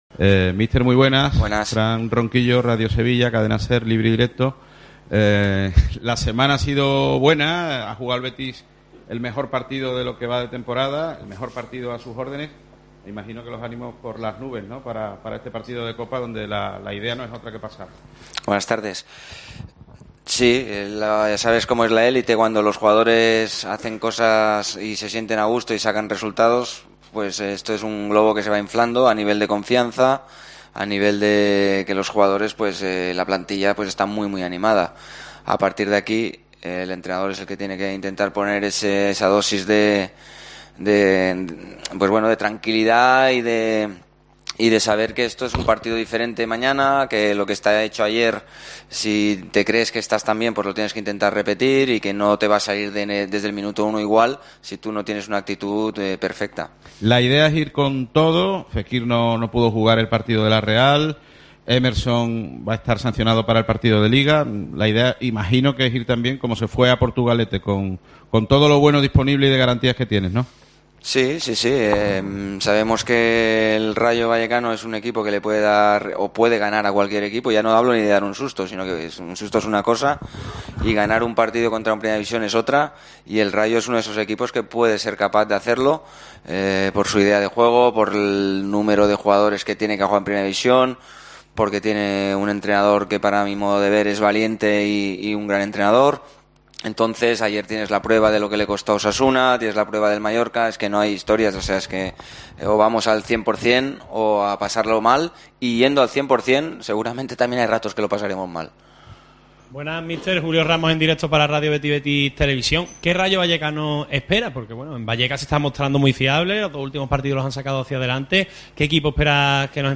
Rubi, en la rueda de prensa previa al partido ante el Rayo